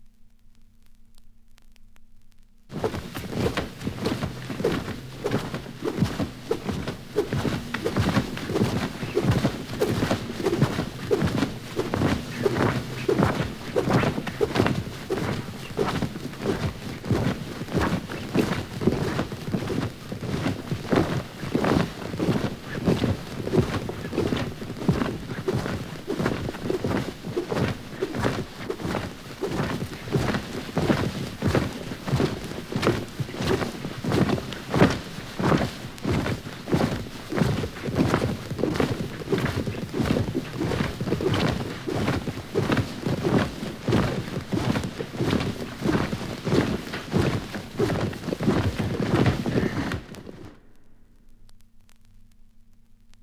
• dracula flying - vampire.wav
Recorded from Sound Effects - Death and Horror rare BBC records and tapes vinyl, vol. 13, 1977.